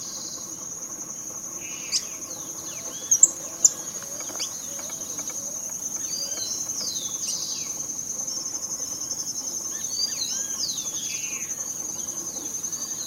Suiriri Flycatcher (Suiriri suiriri)
Life Stage: Adult
Location or protected area: Reserva Natural del Pilar
Condition: Wild
Certainty: Recorded vocal
suiriri-comun.mp3